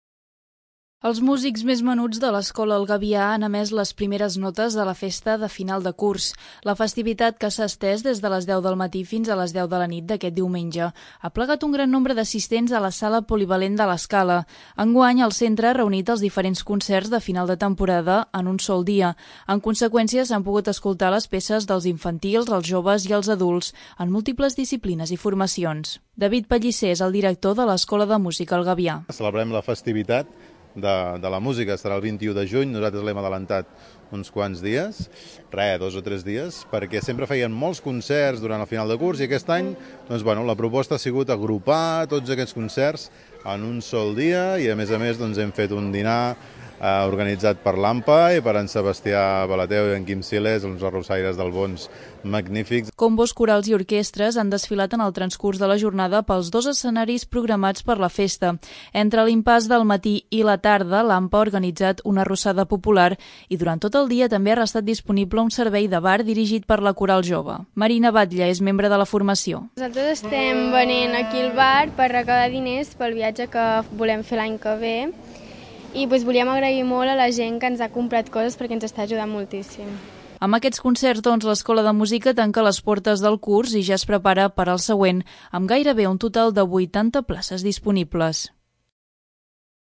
Gairebé una desena de concerts s'han organitzat amb motiu de la festa de final de curs de l'escola de música 'El Gavià'. La trobada, que s'ha realitzat aquest diumenge a la Sala Polivalent, ha comptat amb la participació del conjunt de l'alumnat del centre
Combos, Corals i Orquestres han desfilat en el transcurs de la jornada pels dos escenaris programats per la festa.